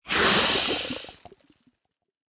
Minecraft Version Minecraft Version 1.21.5 Latest Release | Latest Snapshot 1.21.5 / assets / minecraft / sounds / entity / squid / squirt3.ogg Compare With Compare With Latest Release | Latest Snapshot
squirt3.ogg